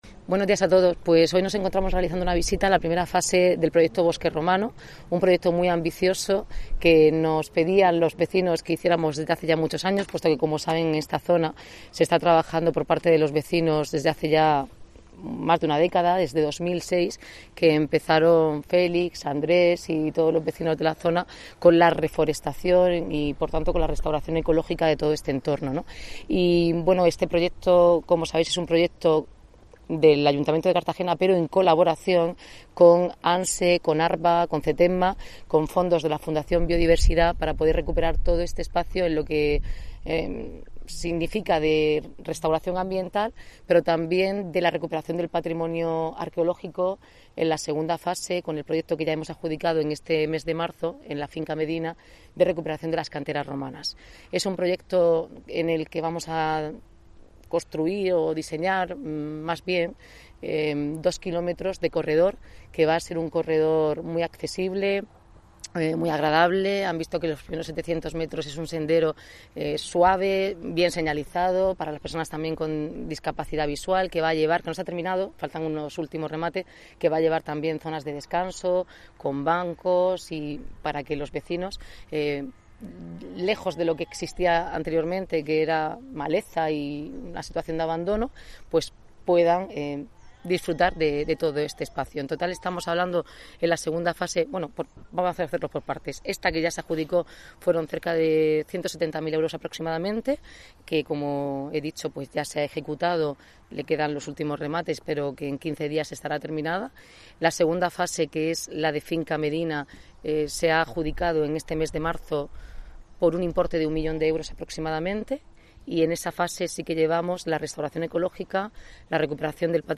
Enlace a Declaraciones Noelia Arroyo